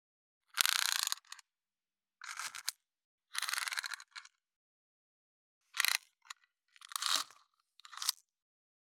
169.メジャーで測る【無料効果音】